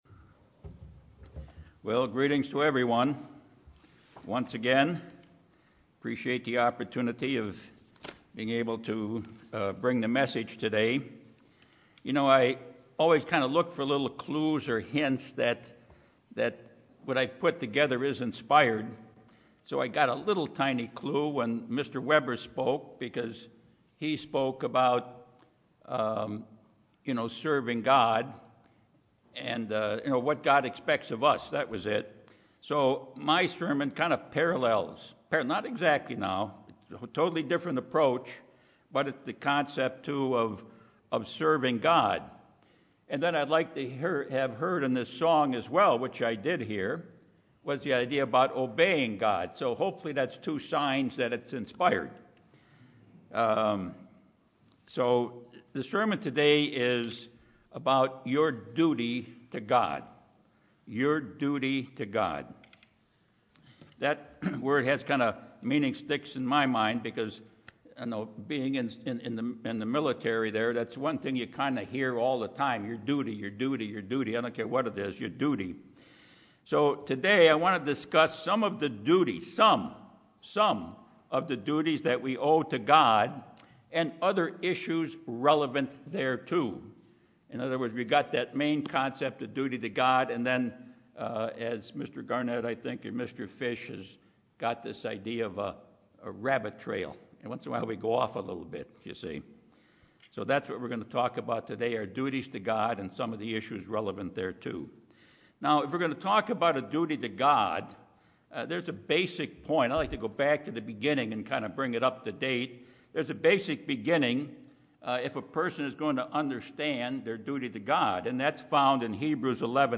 This sermon covers some of the duties we owe to God, urging us to look more closely into God's Word to see what those duties are, to fulfill them and show good works to Him and the world.
Given in Los Angeles, CA